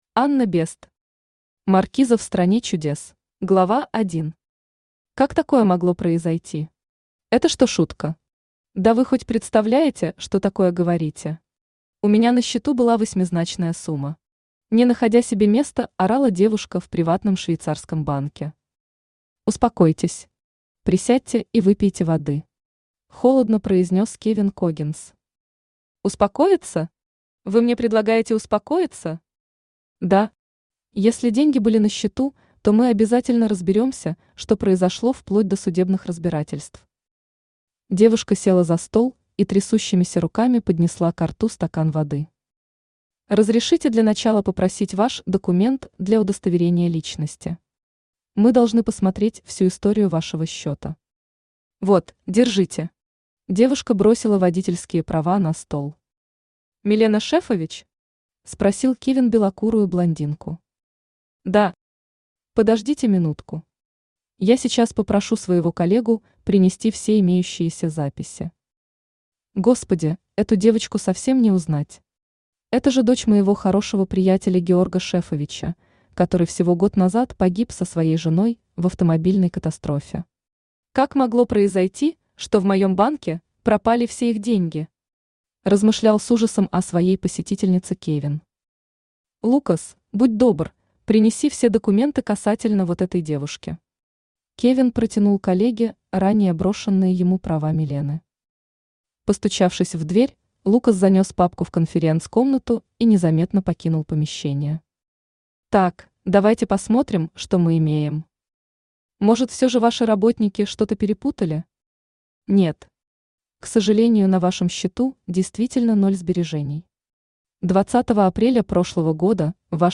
Aудиокнига Маркиза в стране чудес Автор Анна Бесст Читает аудиокнигу Авточтец ЛитРес.